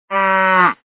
• Funny Ringtones